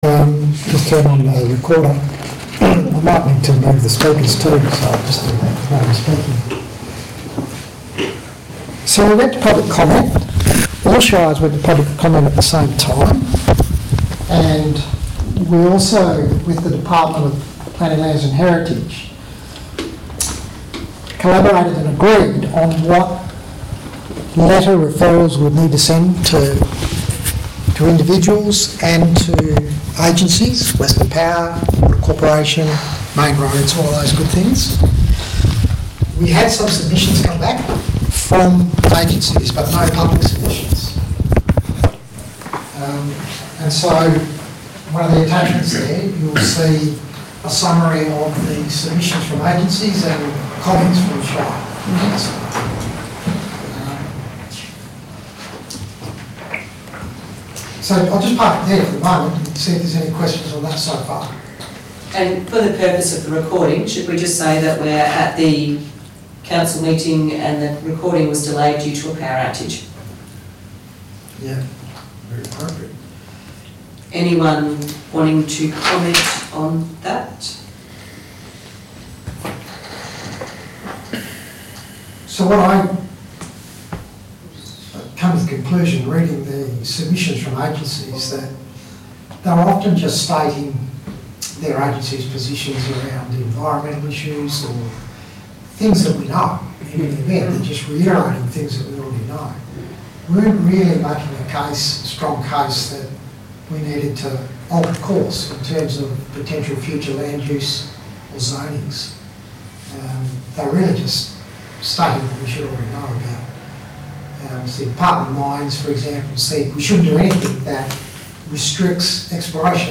Ordinary Meeting of Council - Wednesday 18th June 2025 » Shire of Williams